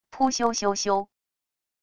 噗咻咻咻――wav音频